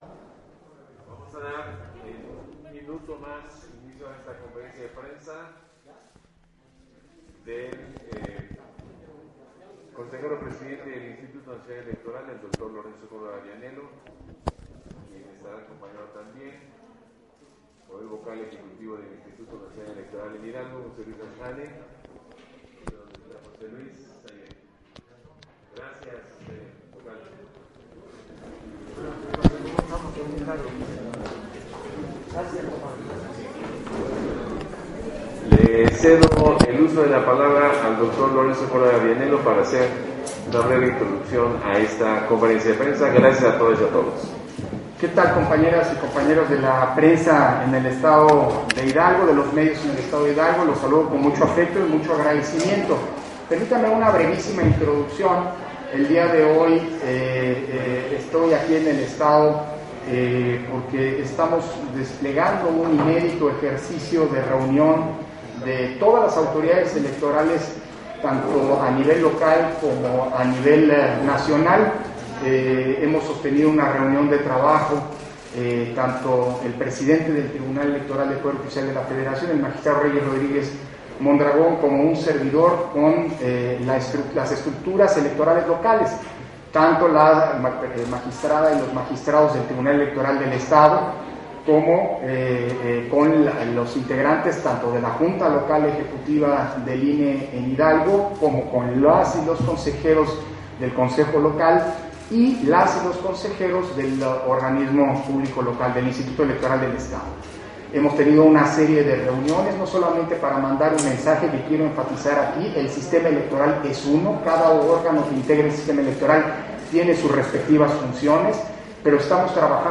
040322_AUDIO_CONFERENCIA-DE-PRENSA-CONSEJERO-PDTE.-CÓRDOVA-HIDALGO - Central Electoral